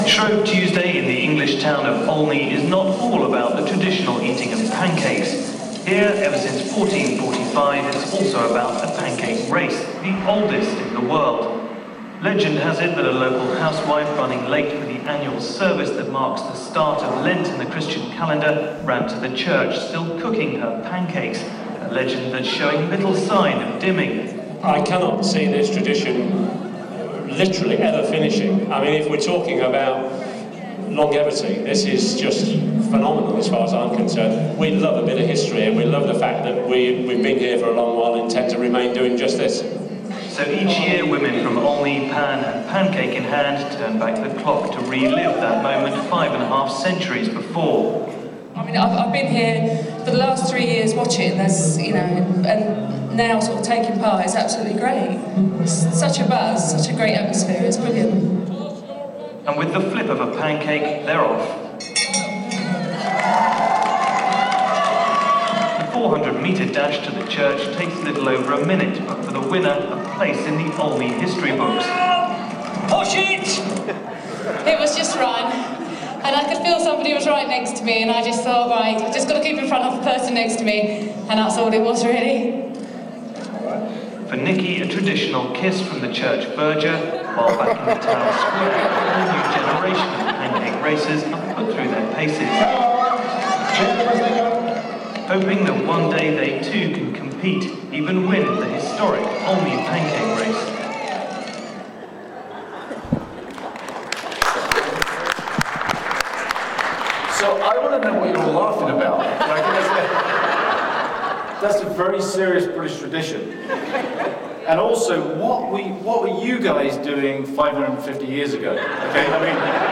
Throughout Lent the messages during the worship services will focus on one or more chapters of the book.